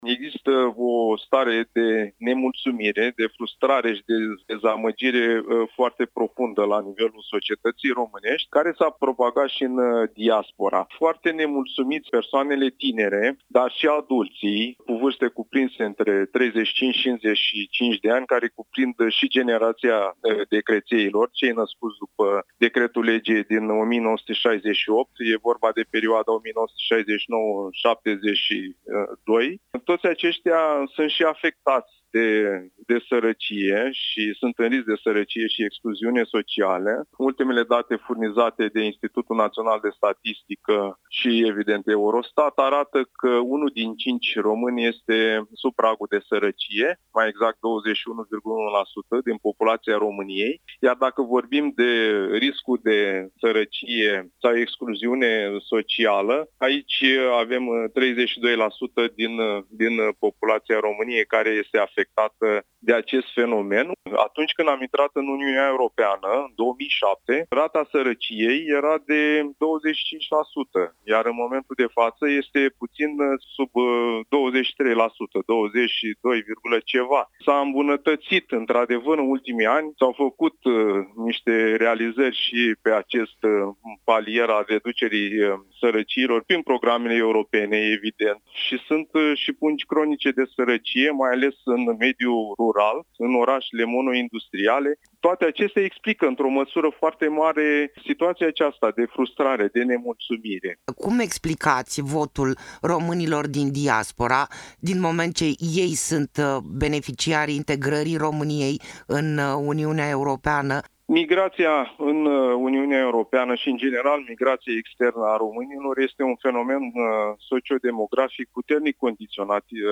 Interviu-harta-votului-sociolog.mp3